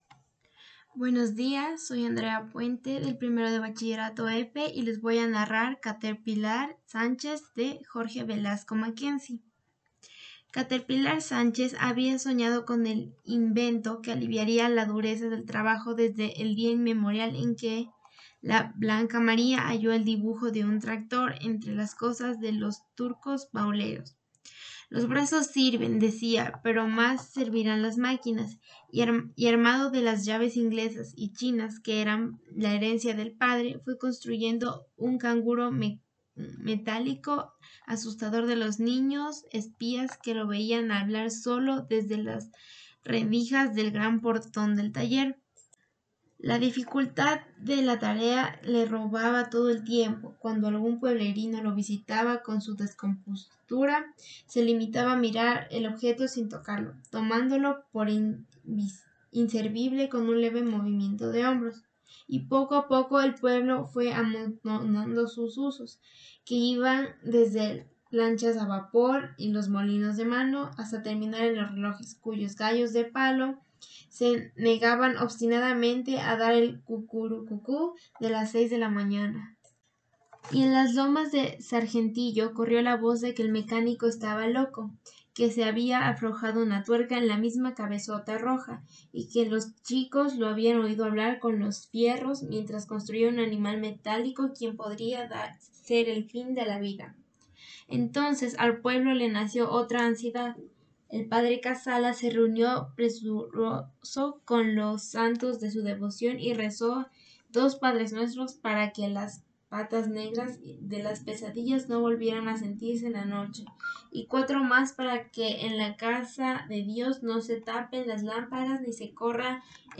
LAfb4LYiCLc_Grabación-Audiolibro.m4a